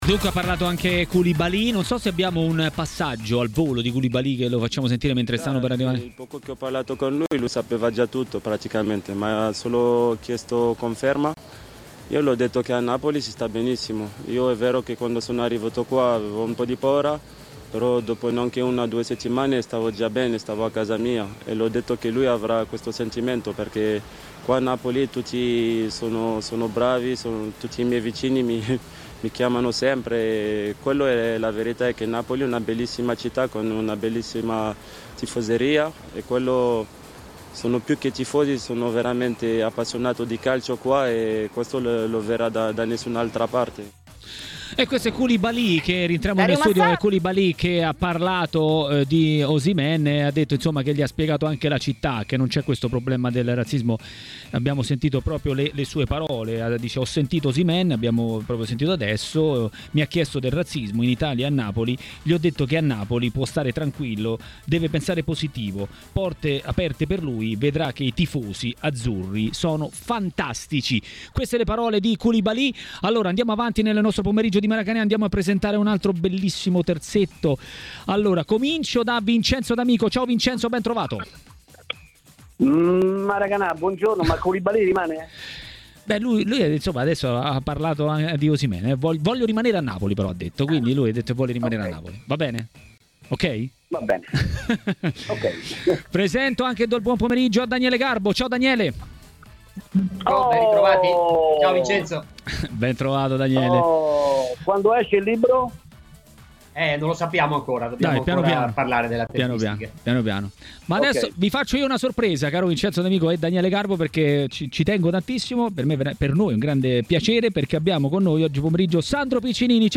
A parlare di campionato a TMW Radio, durante Maracanà, è stato il noto giornalista e telecronista Sandro Piccinini.